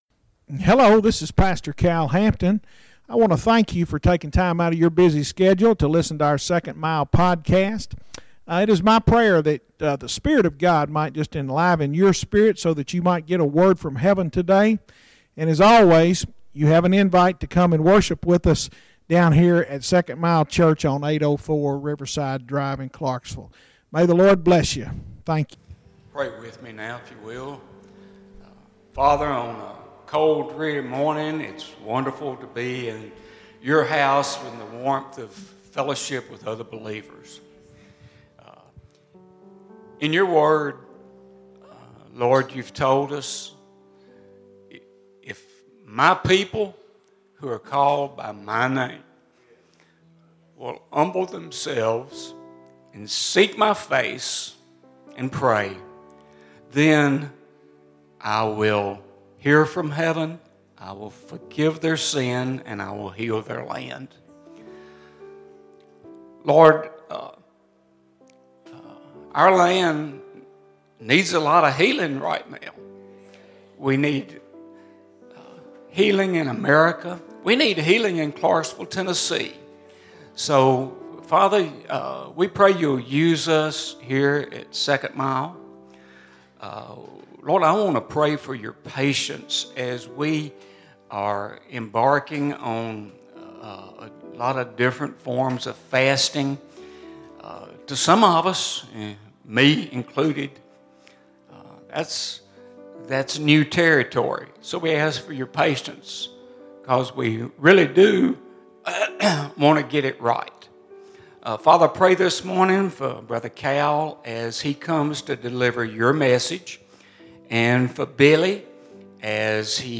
Sermons Archive - Page 117 of 311 - 2nd Mile Church